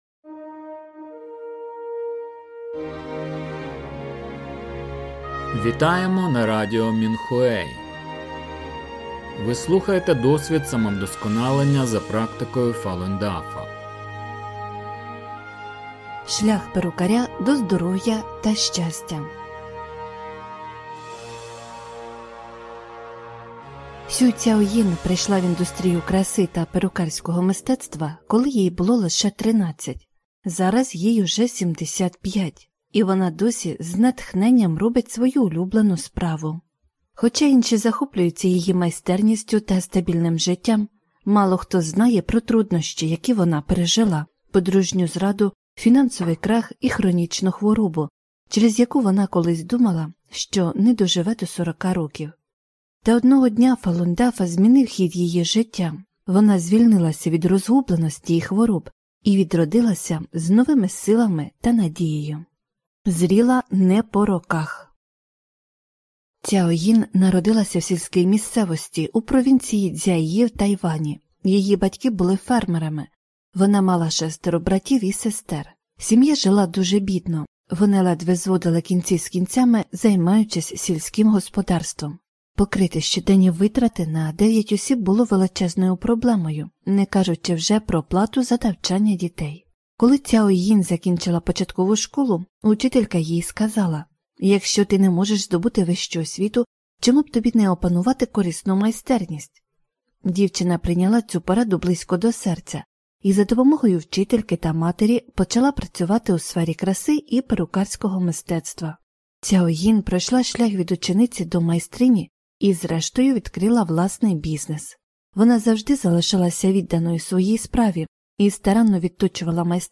Музика з подкастів написана та виконана учнями Фалунь Дафа.